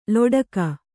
♪ loḍaka